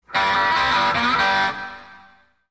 Звуки уведомлений Telegram
Музыка для Телеграм: Звук сообщения